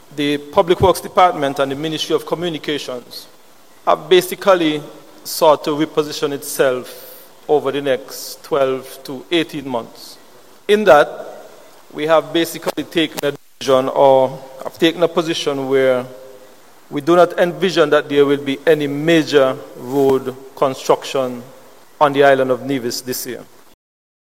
A Town Hall Meeting in the St. Thomas’ Constituency took place at the Jessup’s Community Centre, on July 3rd, 2025, where Minister of Public Works, Physical Planning, et. al, the Hon. Spencer Brand made this statement: